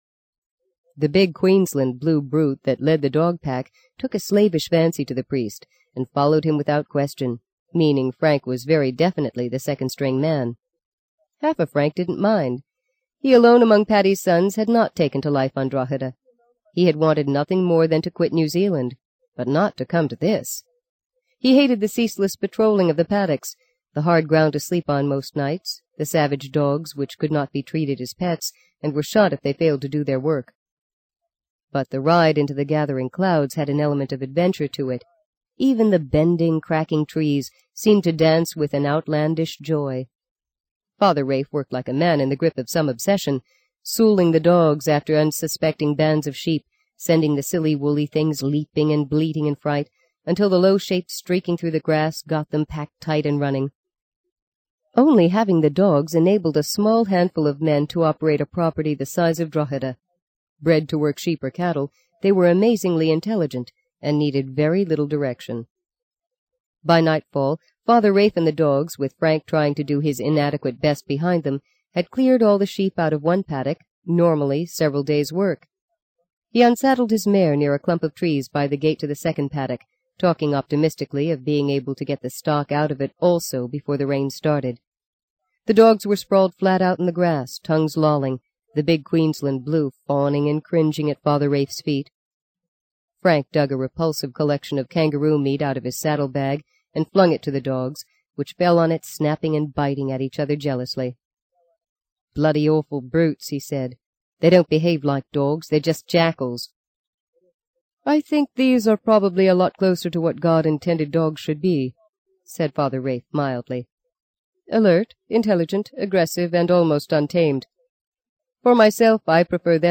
在线英语听力室【荆棘鸟】第四章 10的听力文件下载,荆棘鸟—双语有声读物—听力教程—英语听力—在线英语听力室